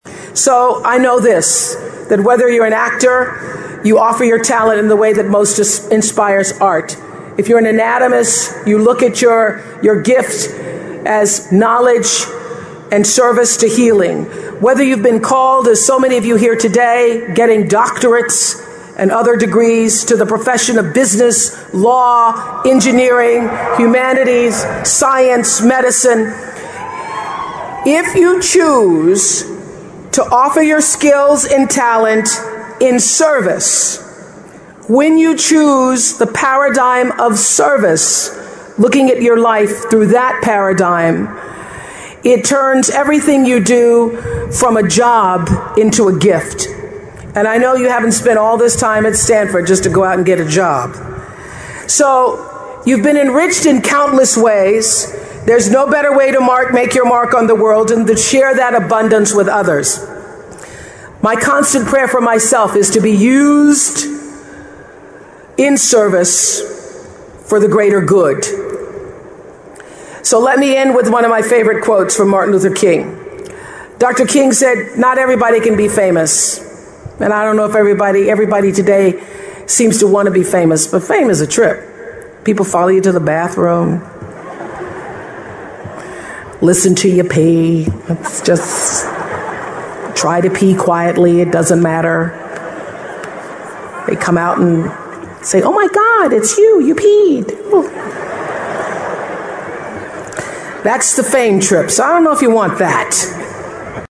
名人励志英语演讲 第153期:感觉失败及寻找幸福(15) 听力文件下载—在线英语听力室